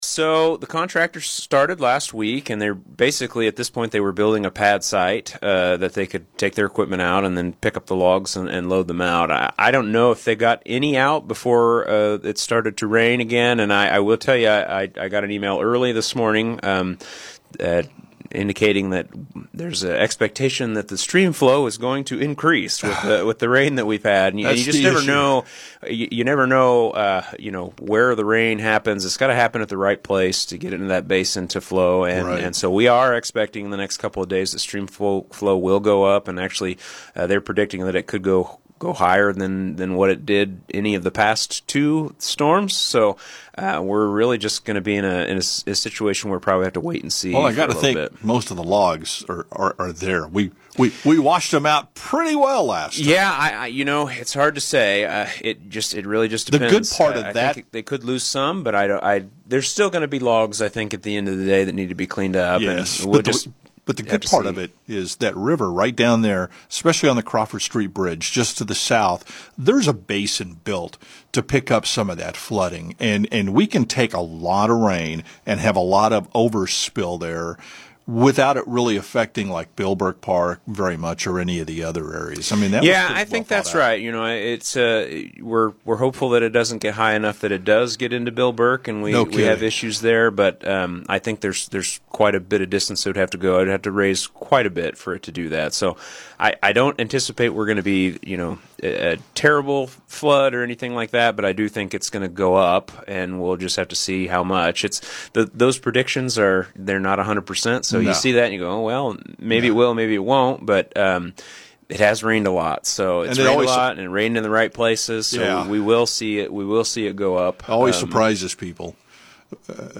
Salina City Manager Jacob Wood, who appeared on KSAL’s “Morning News Extra” Tuesday morning, told KSAL News a pad site was built last week, and removal has started.